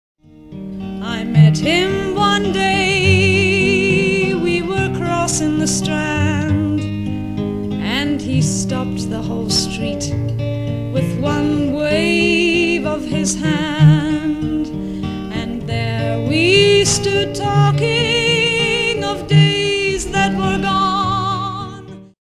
Samples are lower quality for speed.